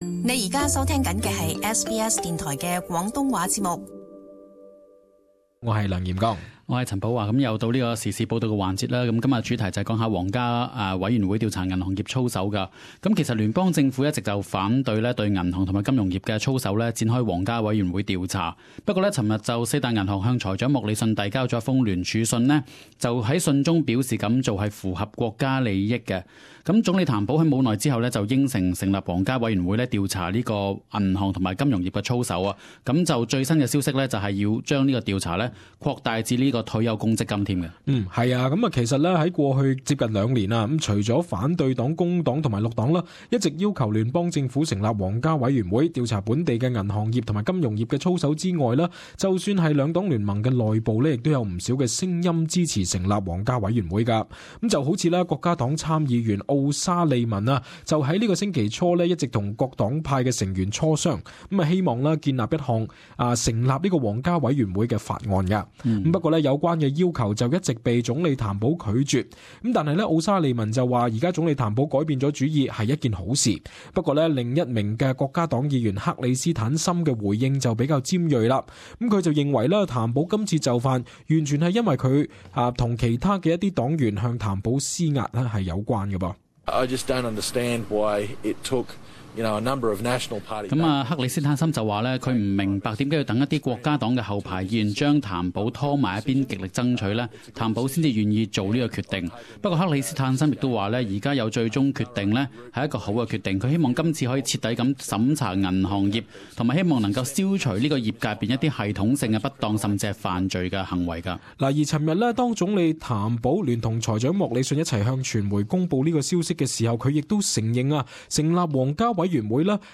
【時事報導】聯邦政府終答允成立皇家委員會調查銀行及金融業操守